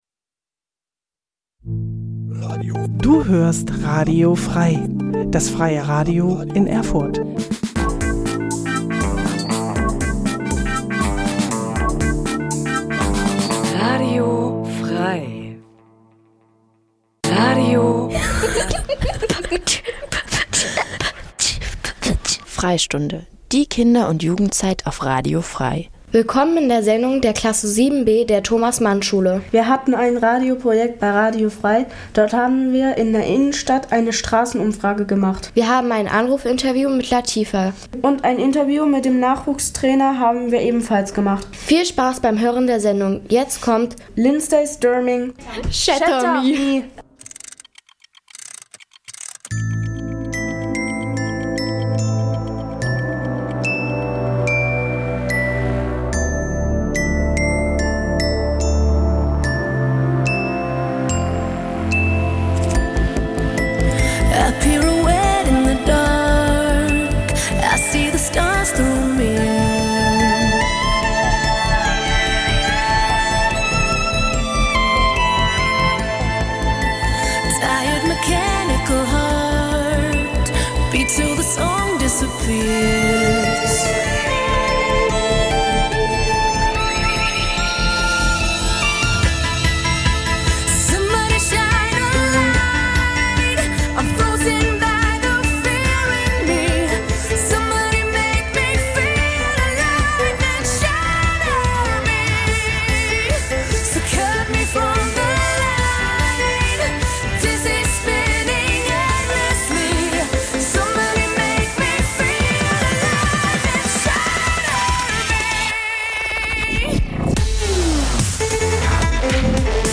Programm von Kindern und Jugendlichen für Kinder und Jugendliche Dein Browser kann kein HTML5-Audio.
F.R.E.I.sprecher jeden ersten Mittwoch im Monat von 17.00 bis 18.00 Uhr Wiederholung am darauf folgenden Sonntag zwischen 12.00 und 13.00 Uhr Die Sendung wird von Kindern zwischen 9 und 13 Jahren produziert.